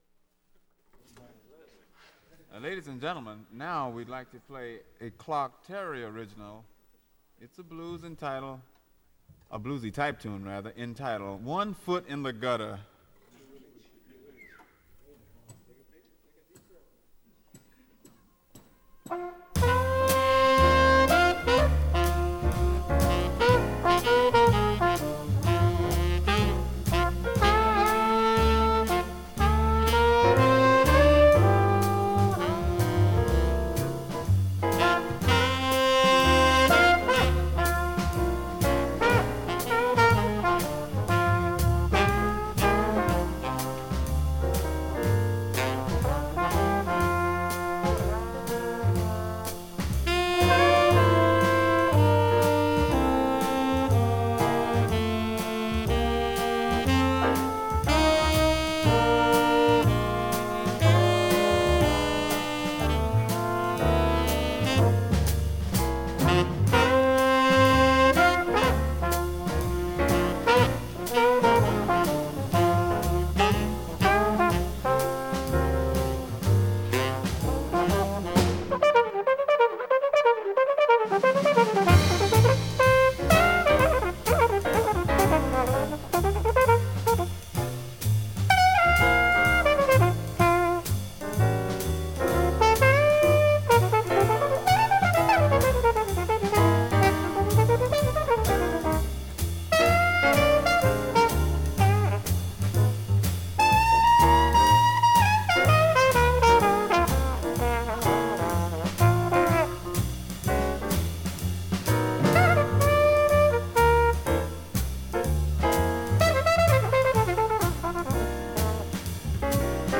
swinging, soulful document of jazz as a living language.